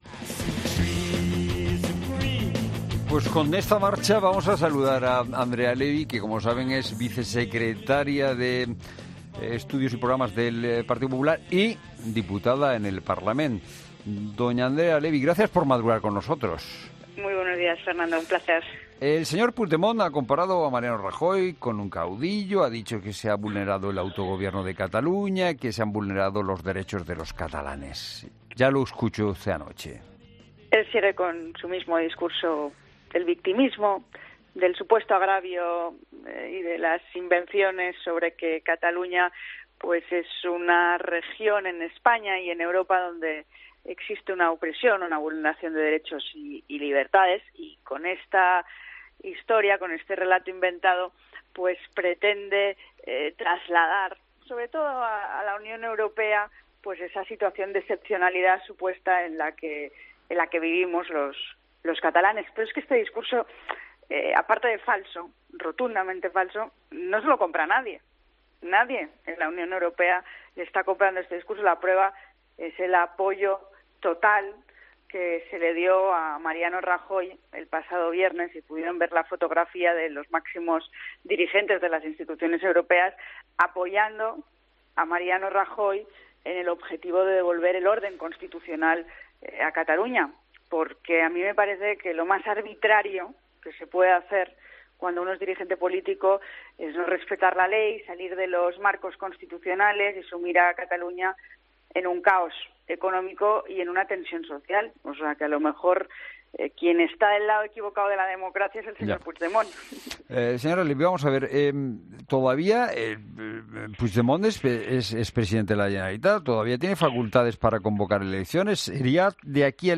AUDIO: Andrea Levy, vicesecretaria de Estudios y Programas del Partido Popular
Entrevista política